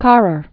(kärər), Paul 1889-1971.